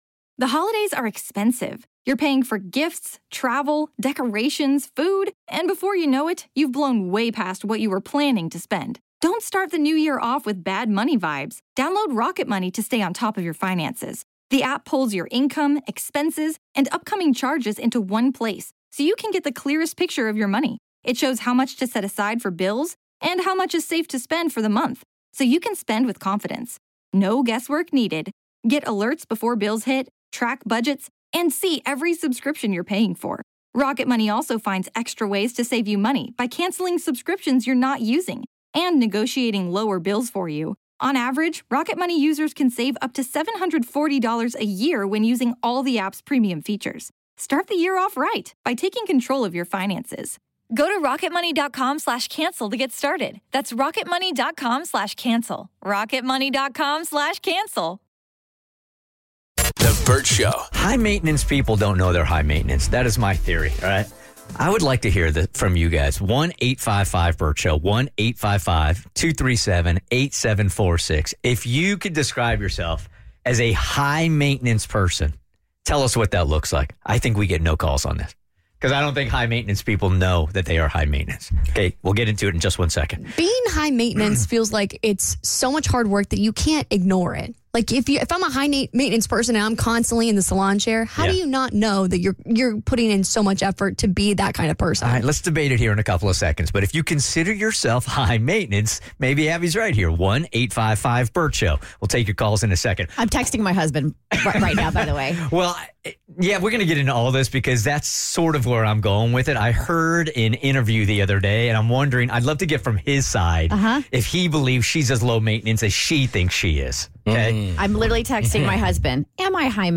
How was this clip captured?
How do you know if you're actually high maintenance or not? One of us asked our significant others if we were high maintenance live on the air...and we got what we asked for!